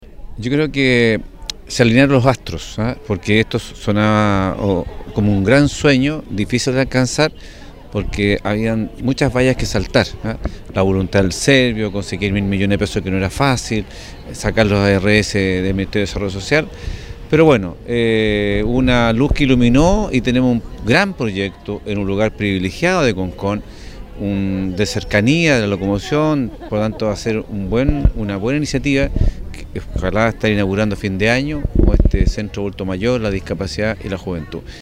Con una tradicional ceremonia, se le dio el vamos oficial a la construcción del futuro Parque Comunitario de Concón.